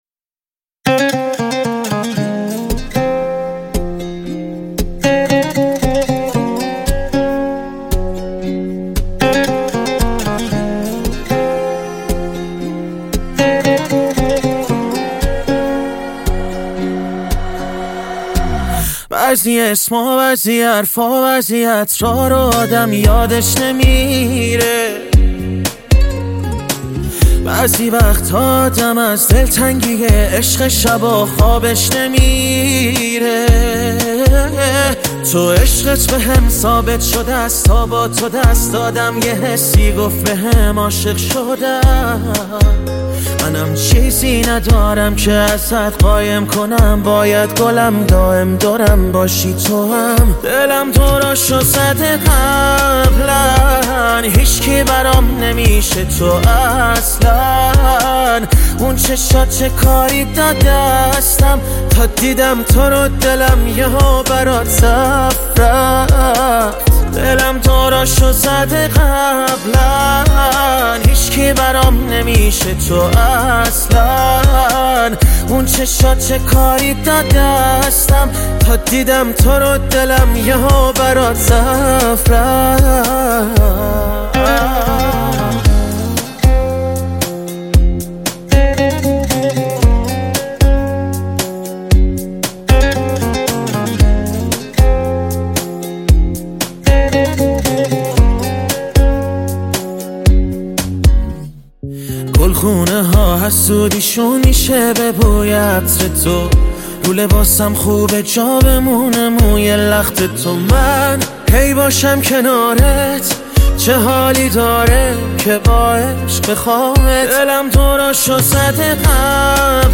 دانلود رایگان پلی بک